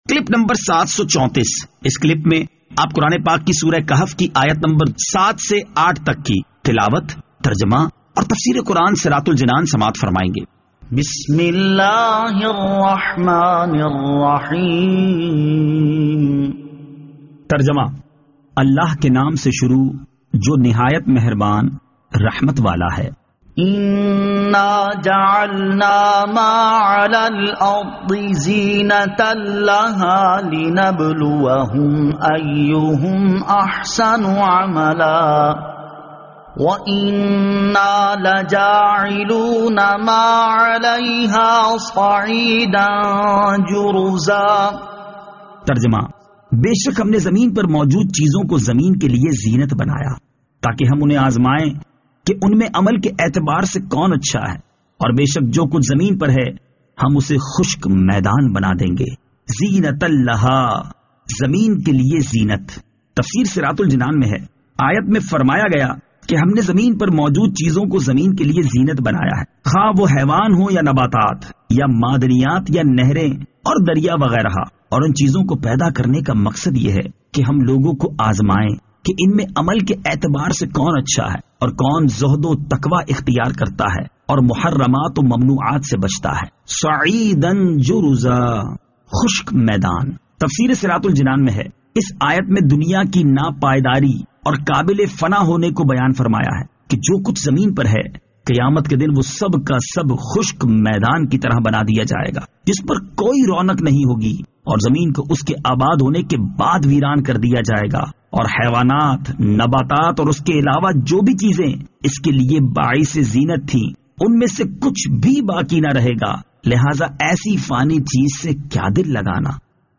Surah Al-Kahf Ayat 07 To 08 Tilawat , Tarjama , Tafseer